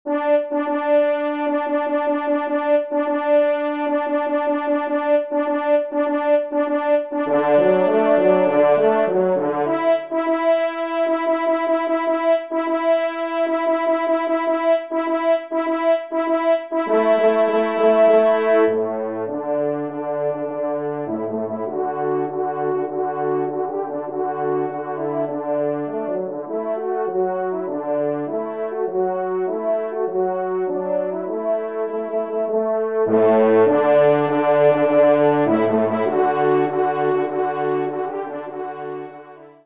Genre :  Divertissement pour Trompes ou Cors en Ré
5e Trompe